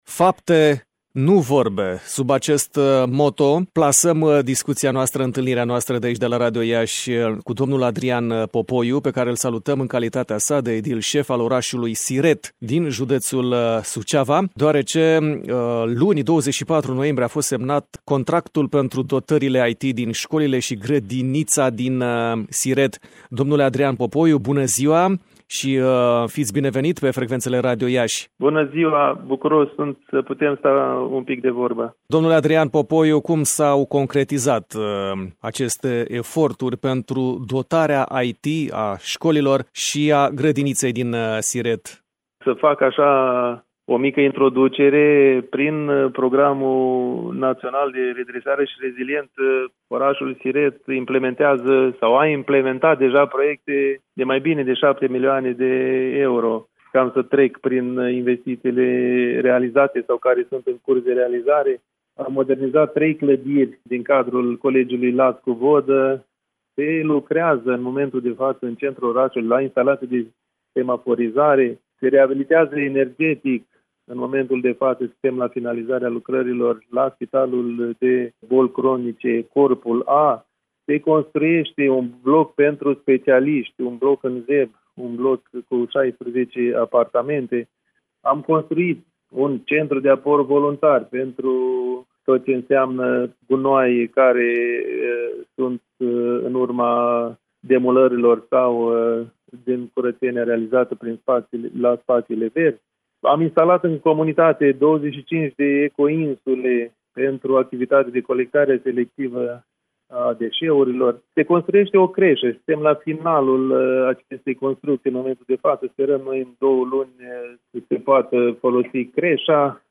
Dotări IT în școlile și grădinița din Siret, județul Suceava.
Sursă: Adrian Popoiu, edilul șef al orașului Siret, județul Suceava.